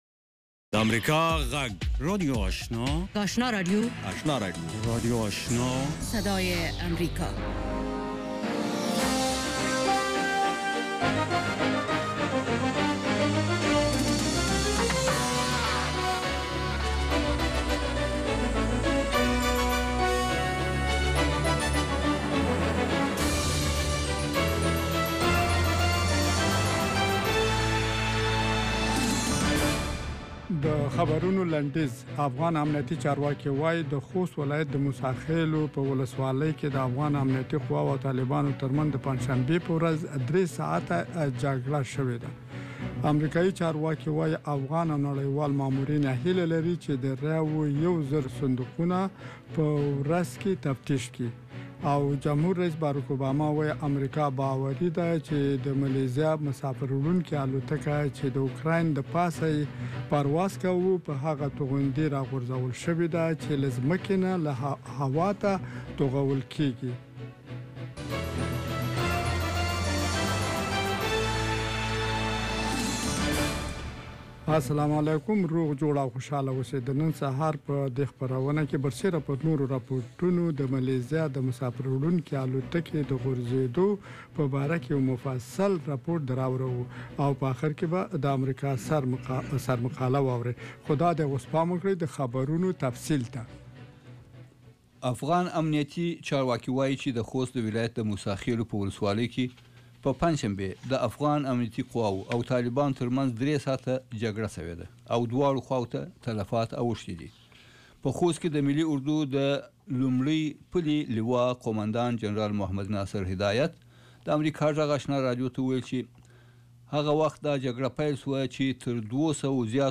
یو ساعته پروگرام: خبرونه